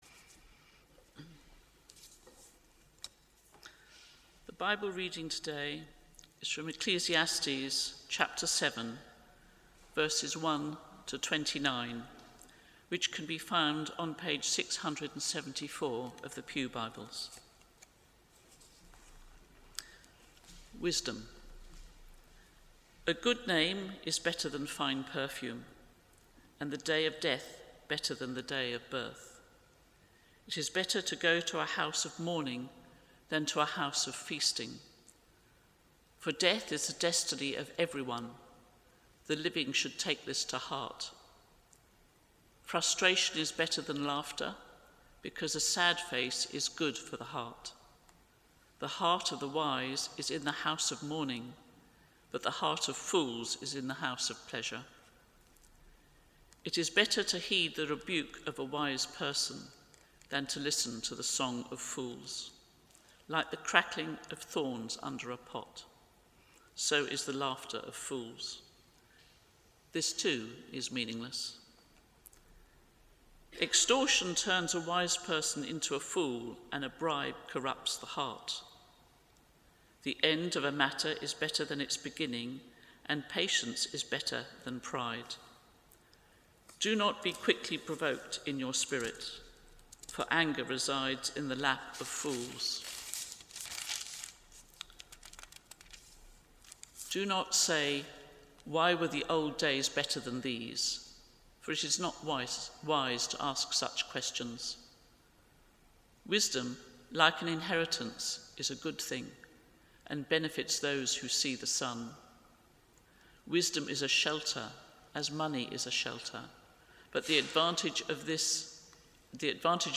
Passage: Ecclesiastes 7:1-29 Service Type: Morning Worship « Riches